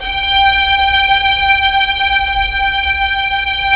Index of /90_sSampleCDs/Roland LCDP13 String Sections/STR_Symphonic/STR_Symph. %wh